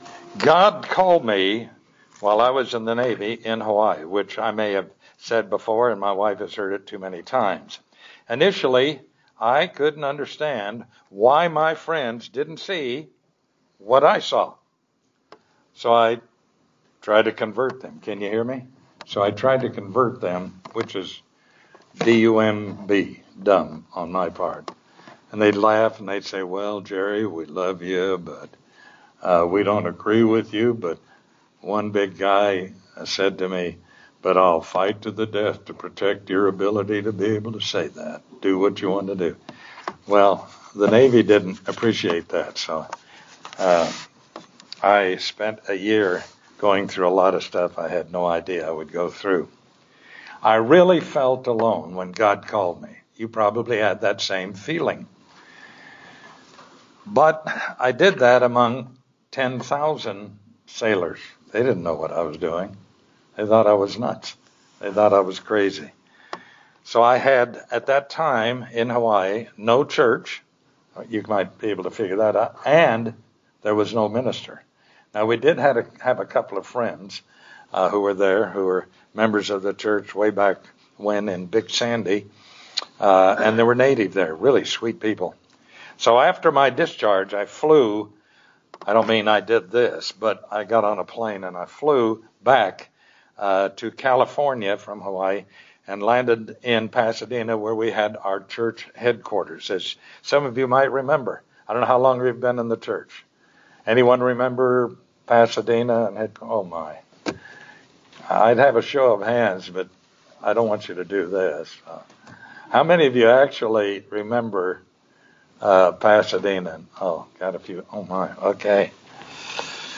In this sermon message, for those whose mind has been opened - they're revealed.